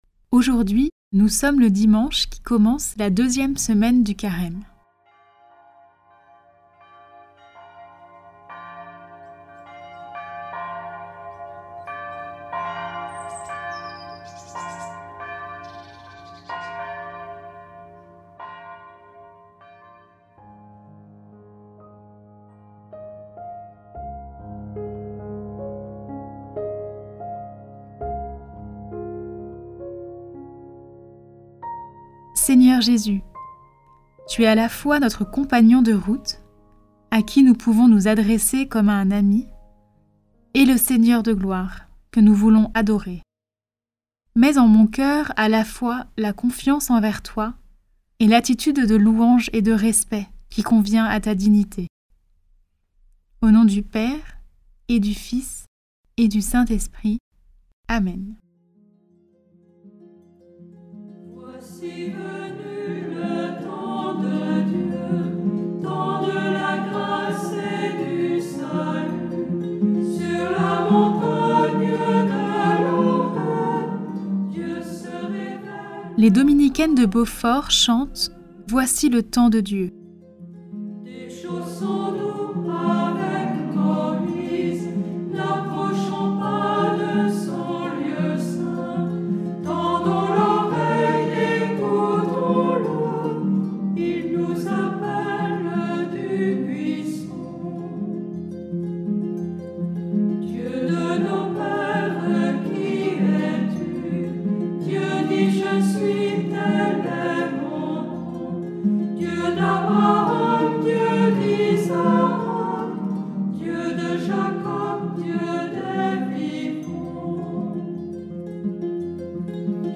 Écouter la méditation avec ‘Prie en Chemin‘ ou la suivre à son rythme avec les pistes ci-dessous.